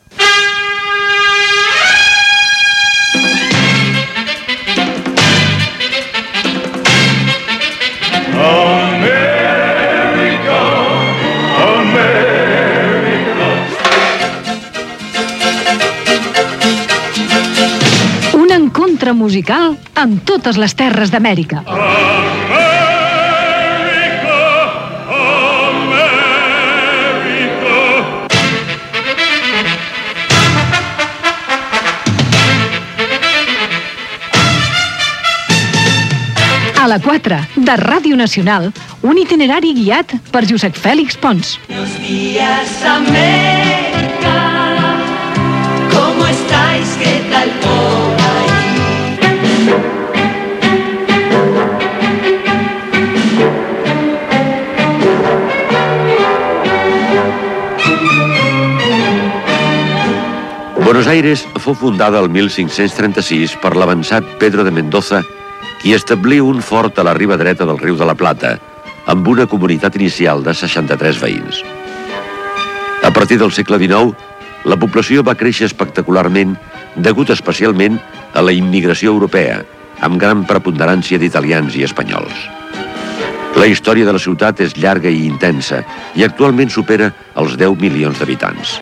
Careta del programa.
Musical